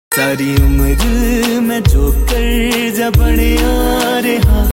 saari umar main joker Meme Sound Effect